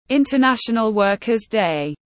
Ngày quốc tế lao động tiếng anh gọi là International Worker’s Day, phiên âm tiếng anh đọc là /ˌɪn.təˈnæʃ.ən.əl ˈwɜː.kər deɪ/
International Worker’s Day /ˌɪn.təˈnæʃ.ən.əl ˈwɜː.kər deɪ/